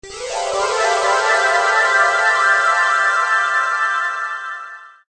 starburst.ogg